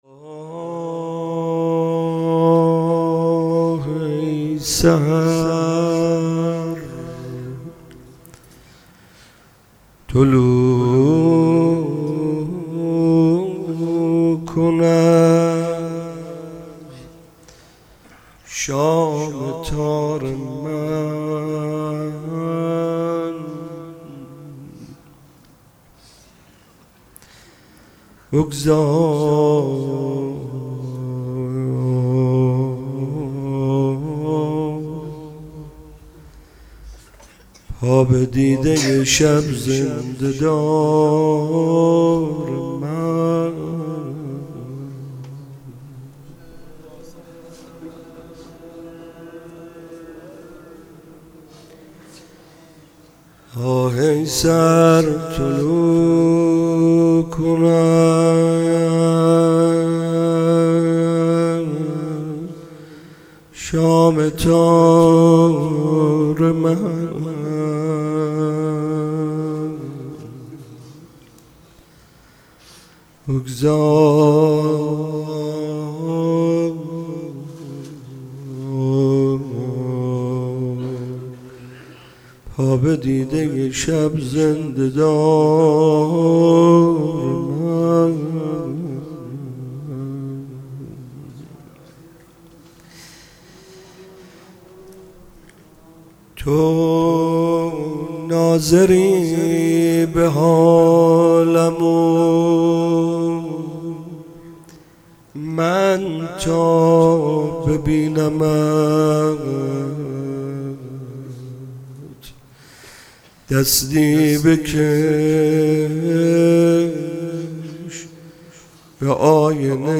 مناجات با امام زمان